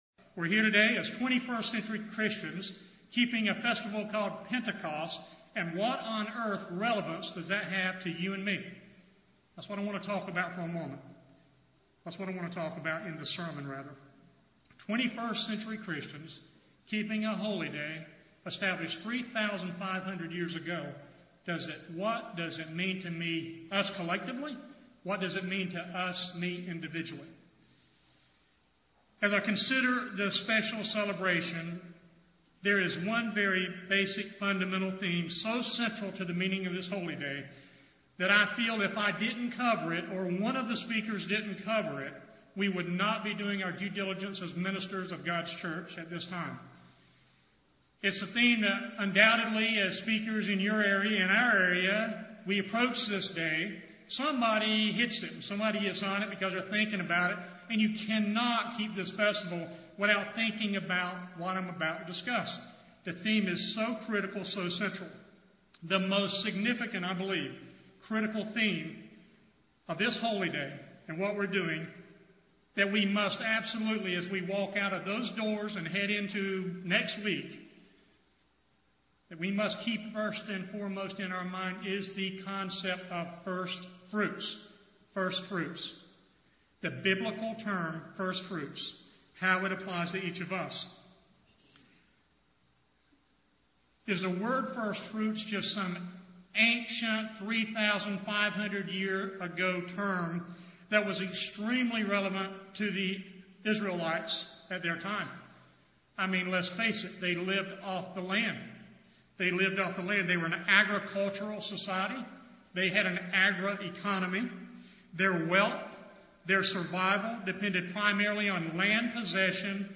Given in Raleigh, NC
UCG Sermon Studying the bible?